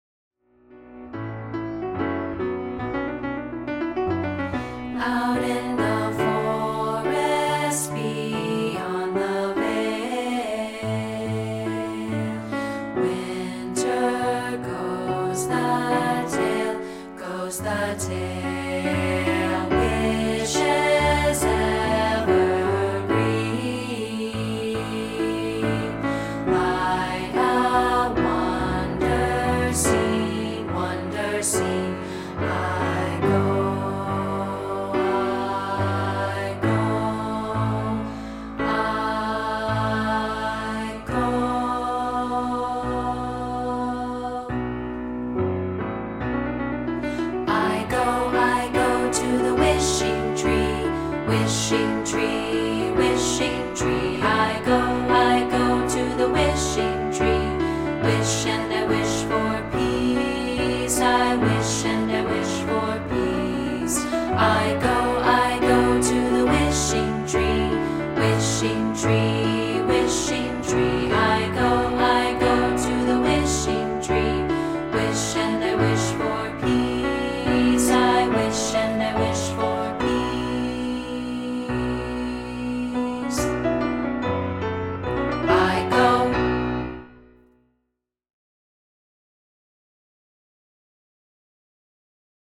We're offering a rehearsal track of part 3, isolated